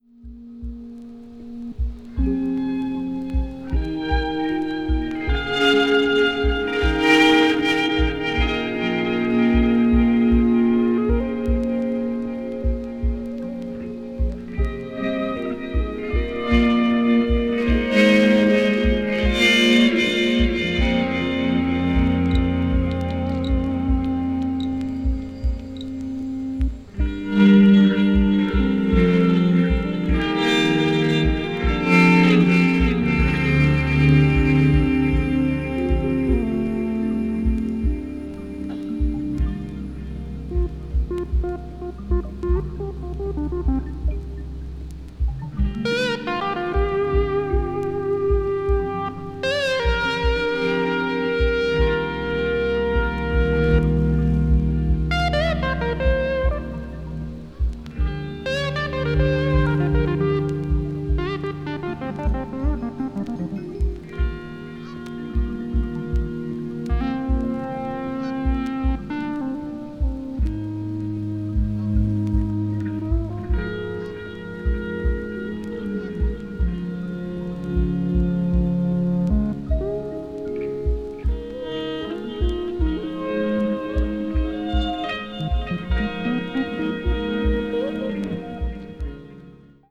メディテーティヴな空気感がたまらないA4も良いですよ。
a.o.r.   blues rock   mellow groove   mellow rock